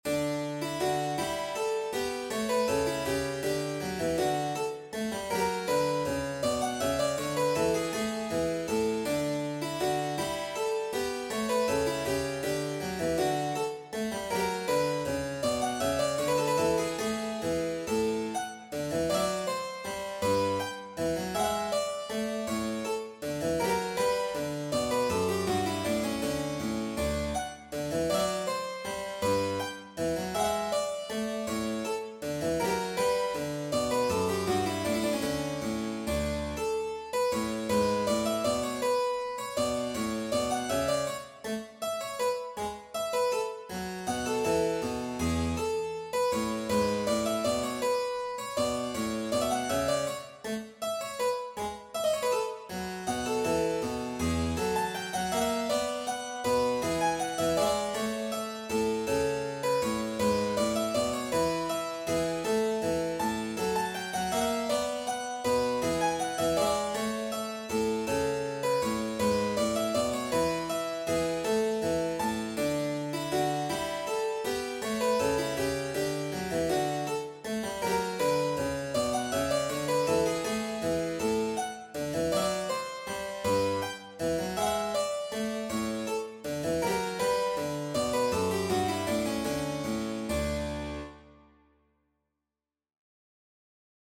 A short Sonata in D Major for Cembalo
Here is the mockup of a piece I submitted to "From Bits to Bangers".